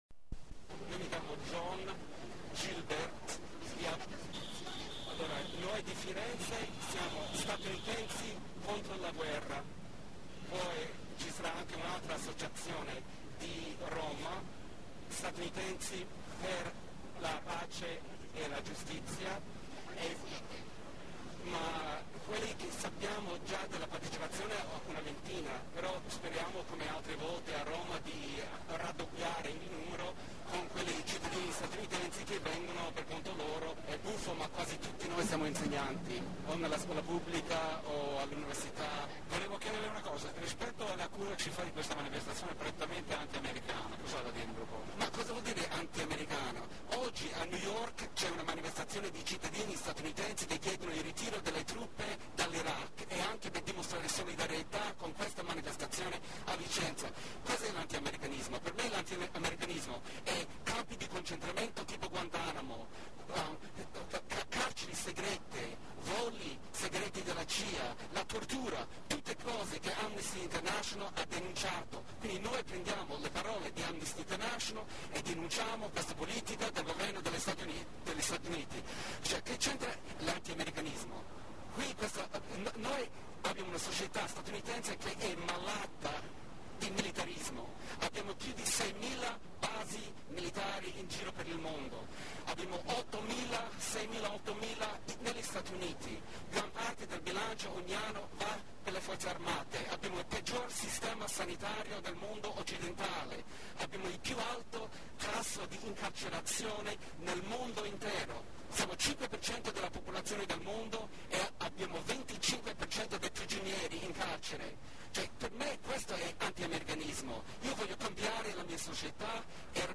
inteviste a Vicenza
Durante la manifestazione di sabato 17 febbraio 2007, molti dei partecipanti ci hanno ricordato che rifiutare la costruzione della seconda base militare vicentina significa opporsi alla dottrina della guerra globale permanente.